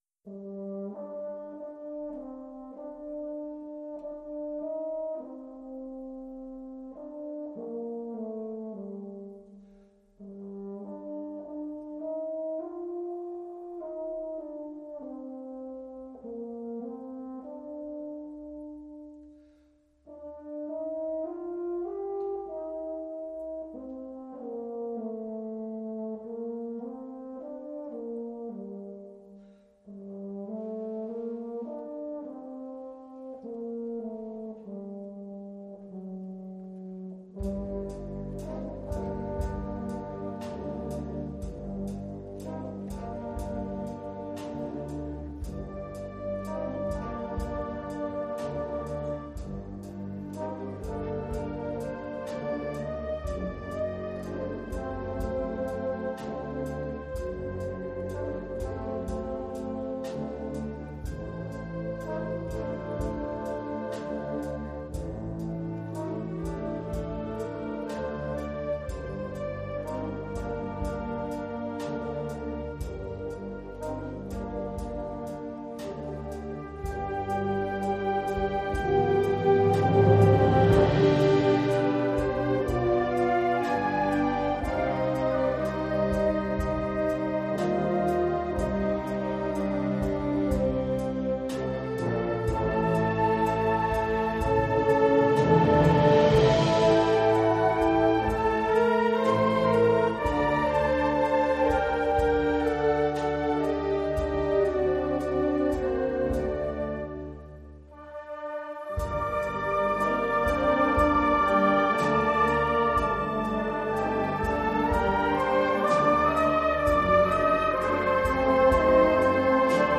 Genre musical : Classique
Oeuvre pour orchestre d’harmonie.
nostalgiques et chaleureuses